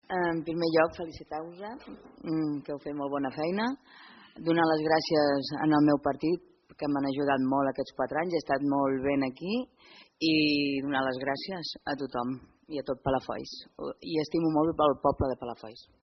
A les files socialistes no repetiran Montserrat Rovira, fins ara regidora d’esports, cultura i festes, que visiblement emocionada es va acomiadar donant les gràcies als seus companys del PSC i al poble de Palafolls